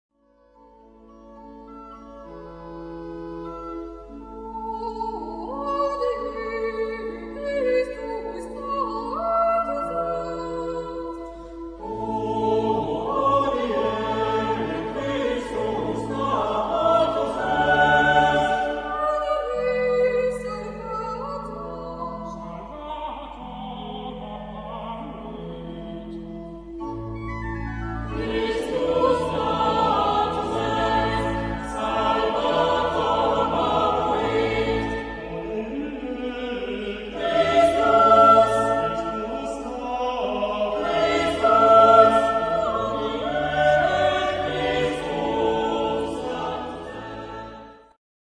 Zeitepoche: 19. Jh.
Genre-Stil-Form: Motette ; geistlich
Chorgattung: SATB  (4 gemischter Chor Stimmen )
Instrumentation: Orgel
Tonart(en): A-Dur